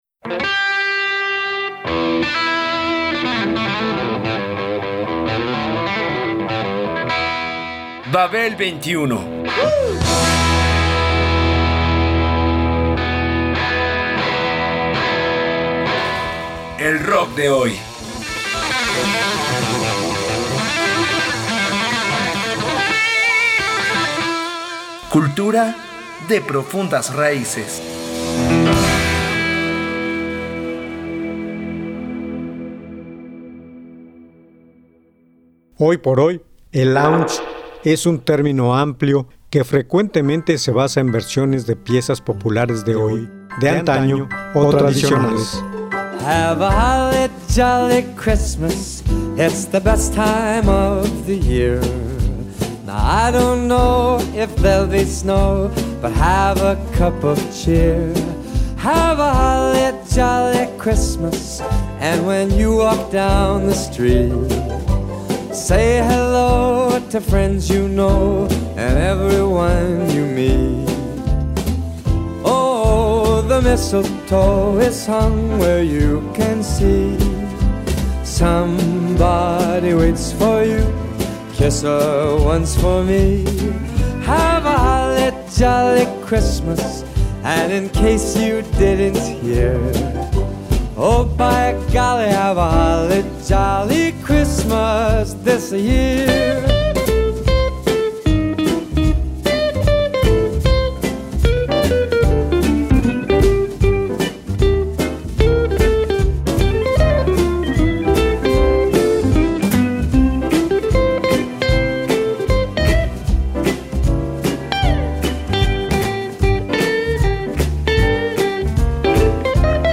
Se caracteriza por presentar ritmos sensuales y provistos de una instrumentación sofisticada.